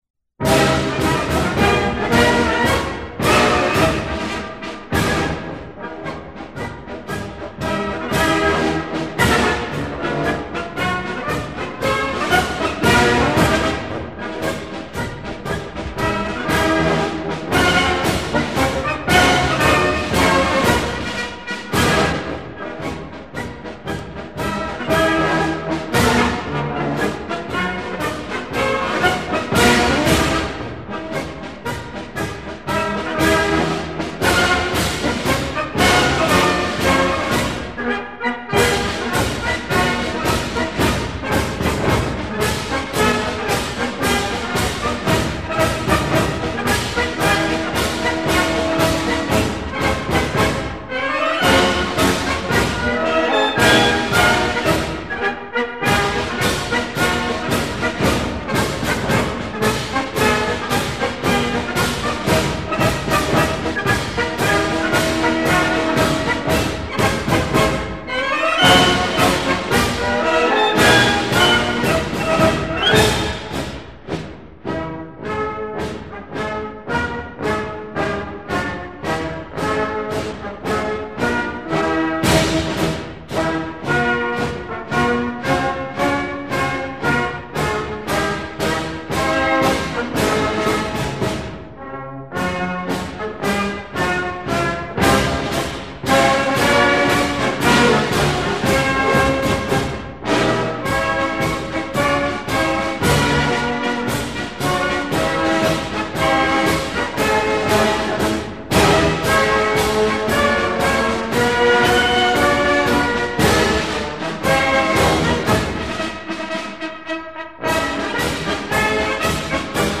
Austrian Marches - Andreas Hofer March.mp3